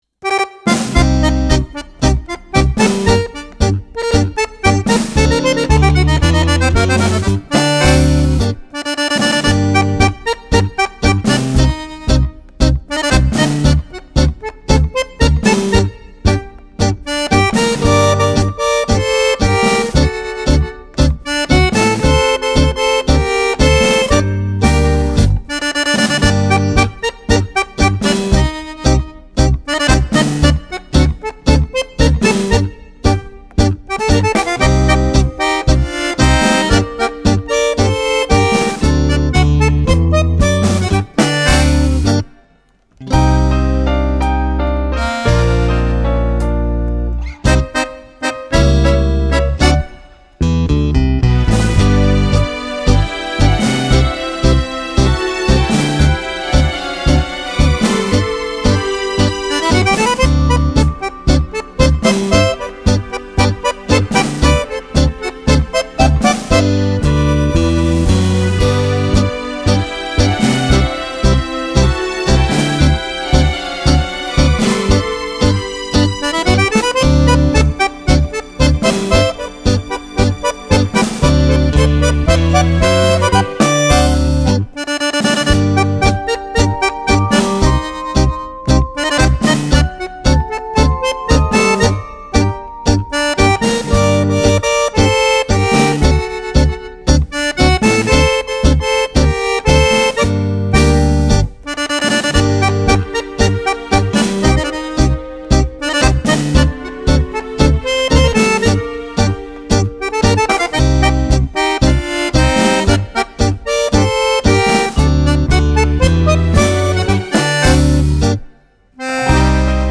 Genere: Tango